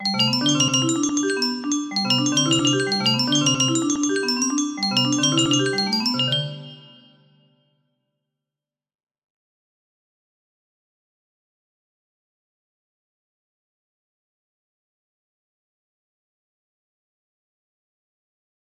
La Cantata nº 147 music box melody